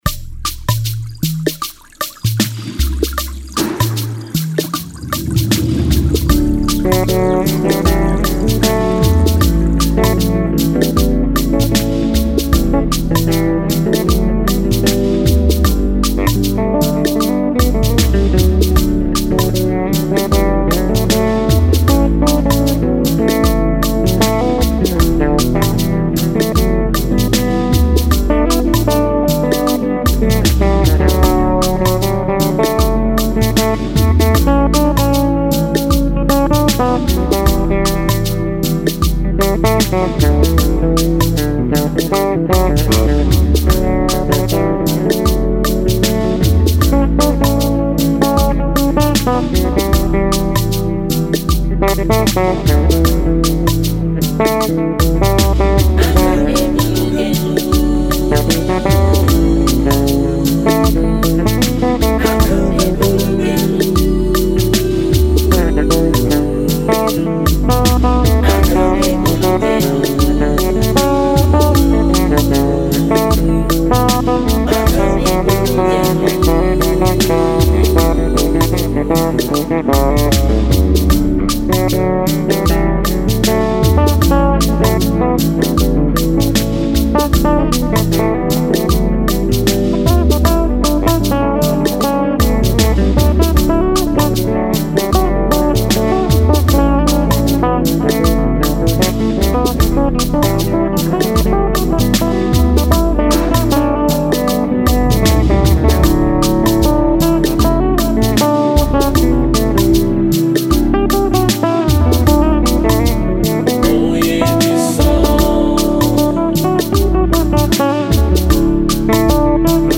instrumental medley of four powerful Thanksgiving songs